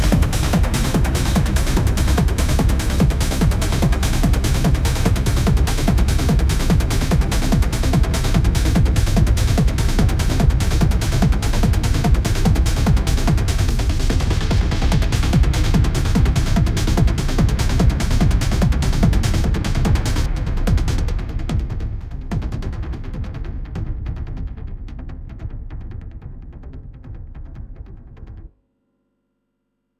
audio-to-audio industrial-techno music-generation
MusicGen Stereo Medium fine-tuned on industrial techno with the text token "construction hymn"